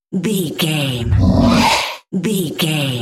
Whoosh creature growl fast
Sound Effects
Atonal
Fast
ominous
eerie